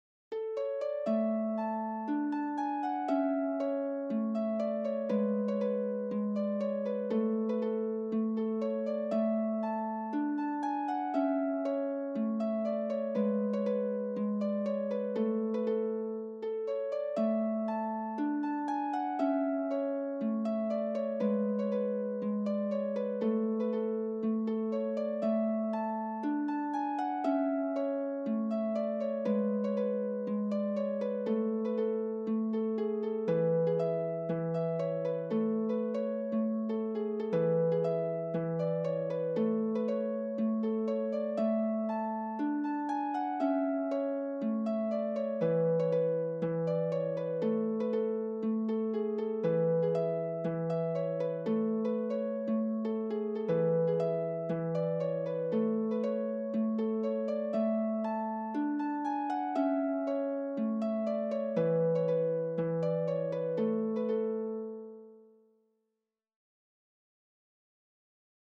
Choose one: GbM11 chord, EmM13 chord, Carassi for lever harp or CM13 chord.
Carassi for lever harp